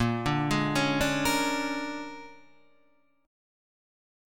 A#7#9 chord {6 8 6 7 9 9} chord